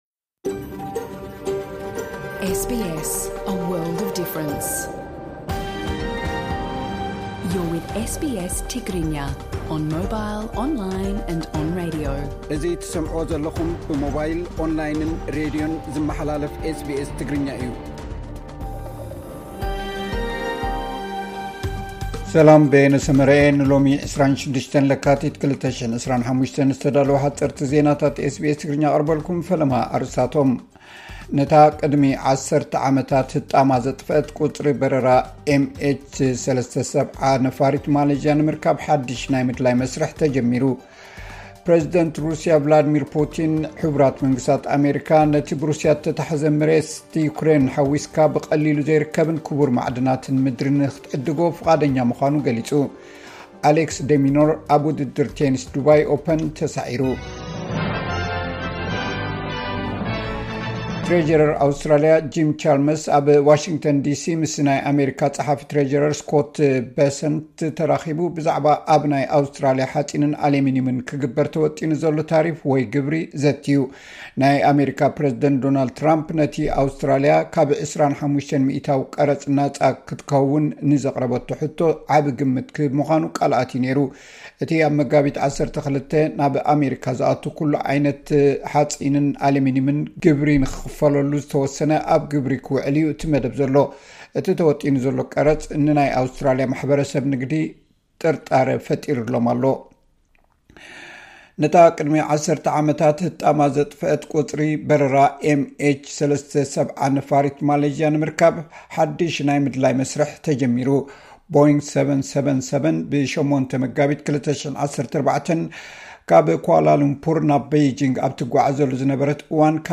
ዕለታዊ ዜና ኤስ ቢ ኤስ ትግርኛ (26 ለካቲት 2025)